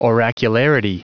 Prononciation du mot oracularity en anglais (fichier audio)